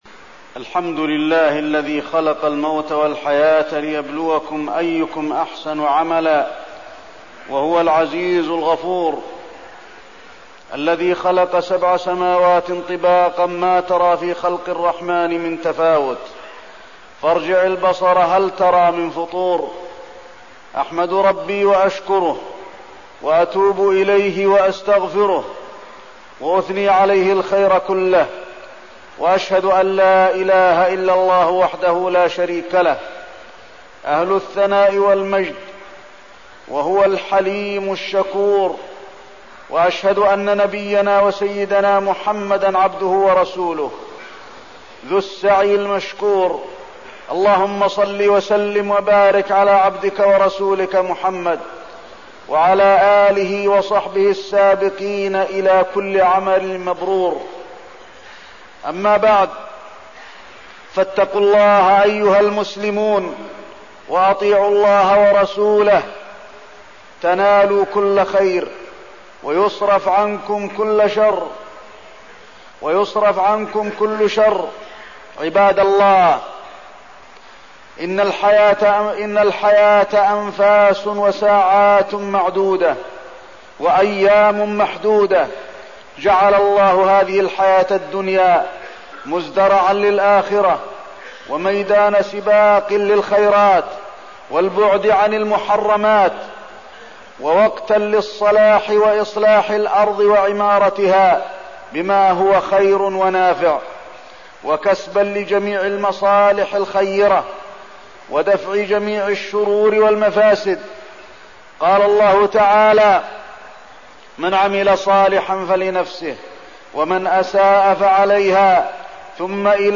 تاريخ النشر ٢٤ ربيع الأول ١٤١٤ هـ المكان: المسجد النبوي الشيخ: فضيلة الشيخ د. علي بن عبدالرحمن الحذيفي فضيلة الشيخ د. علي بن عبدالرحمن الحذيفي اغتنام الأوقات